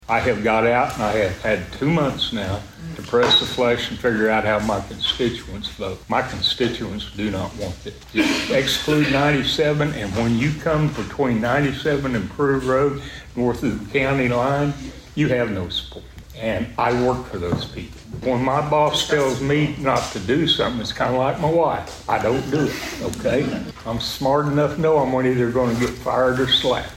As discussed during Monday's Osage County Commissioners meeting though, there has been some pushback to the development of the data center.